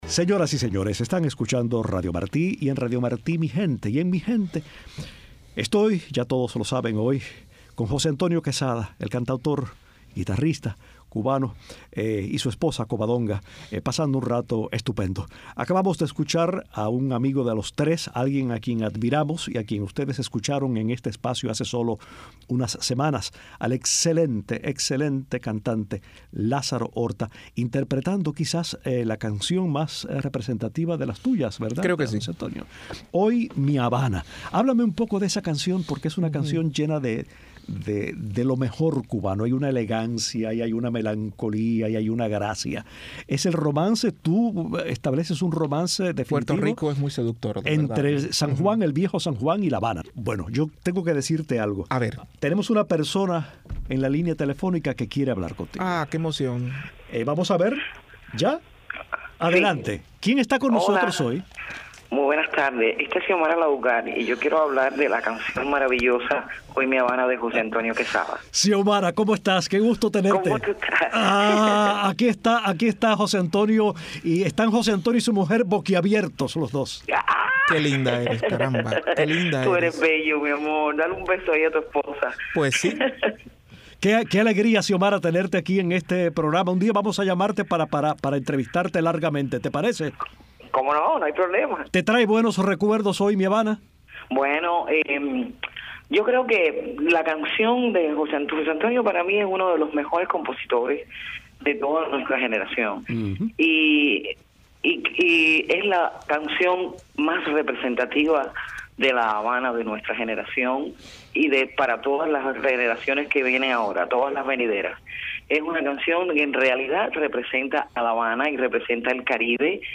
Entrevista a la cantante cubana Daymé Arocena sobre su nuevo álbum Al-Kemi